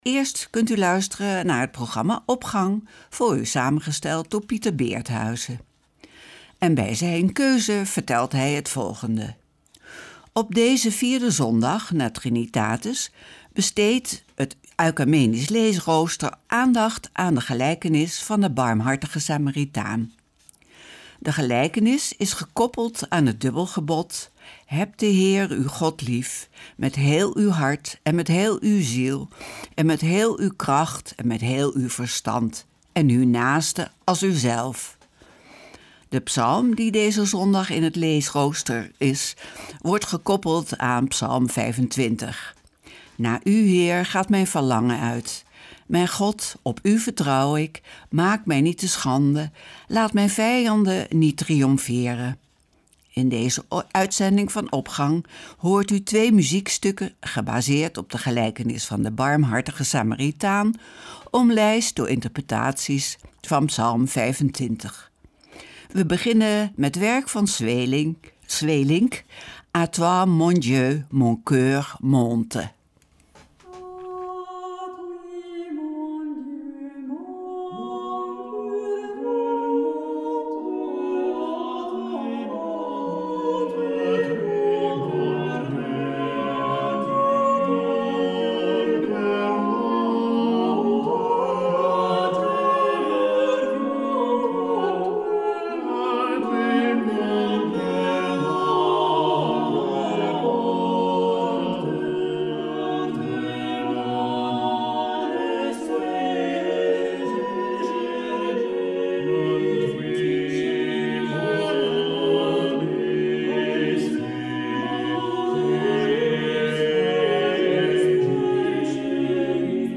Opening van deze zondag met muziek, rechtstreeks vanuit onze studio.
In deze uitzending van Opgang hoort u twee muziekstukken gebaseerd op de gelijkenis van de barmhartige Samaritaan, omlijst door interpretaties van psalm 25.